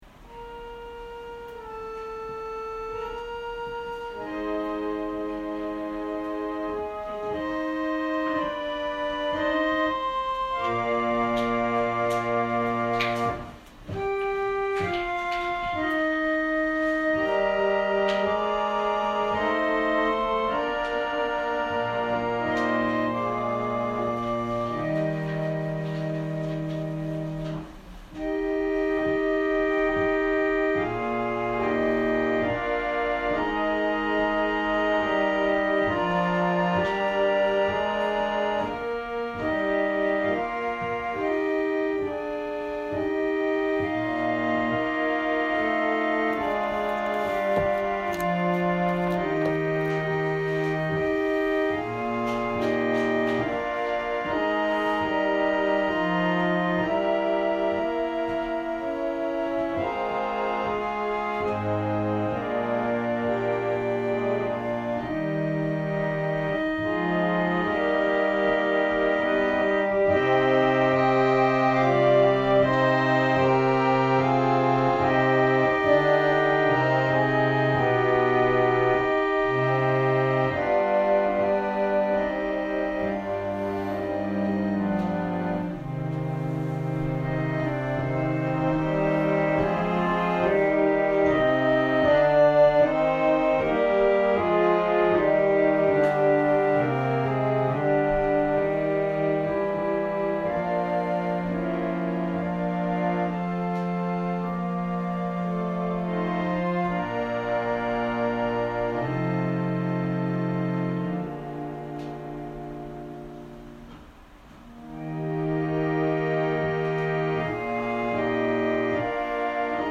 説教アーカイブ。